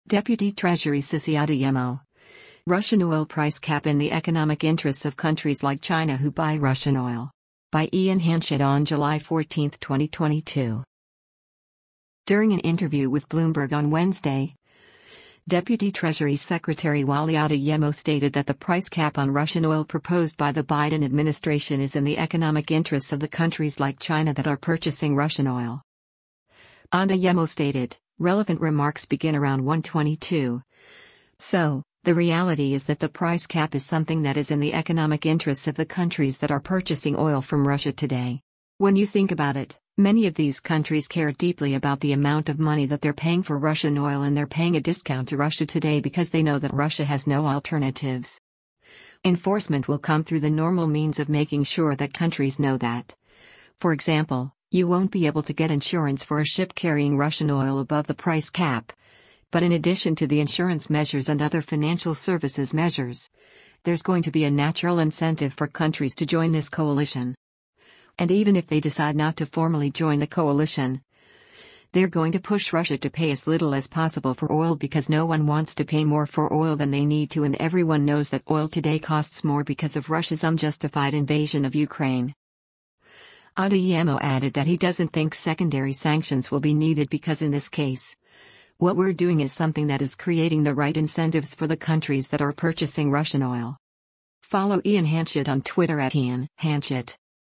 During an interview with Bloomberg on Wednesday, Deputy Treasury Secretary Wally Adeyemo stated that the price cap on Russian oil proposed by the Biden administration “is in the economic interests of the countries” like China that are purchasing Russian oil.